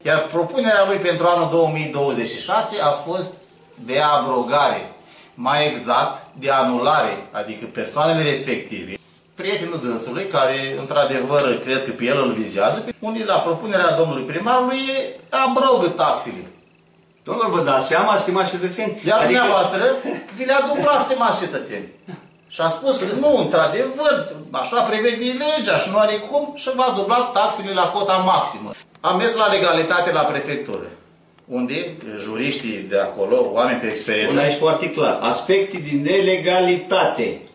Insert audio – consilieri local din opoziție